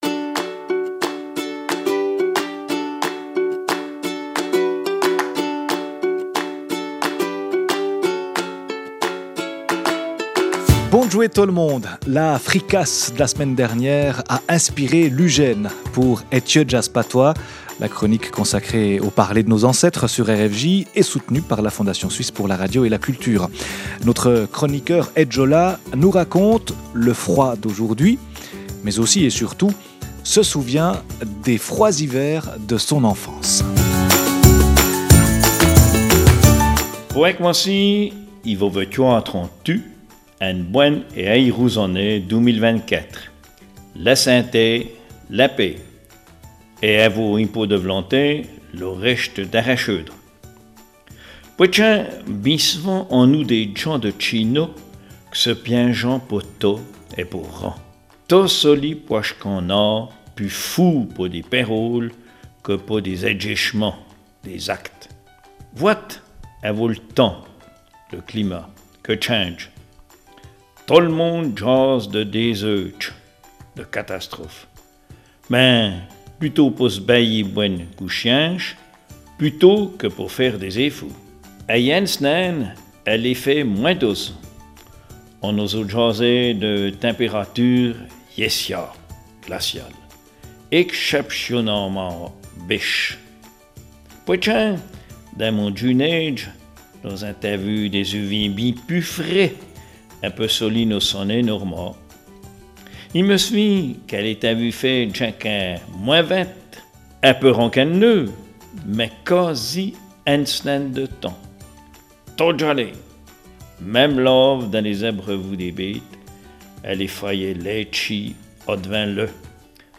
Radio Fréquence Jura RFJ rubrique en patois,